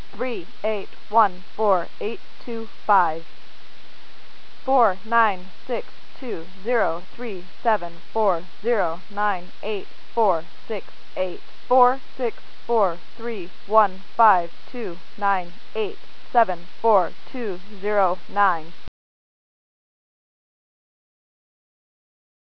Once more, you will hear a list of 7 numbers.
You will hear another list of numbers after that.